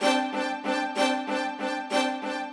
GS_Viols_95-D1.wav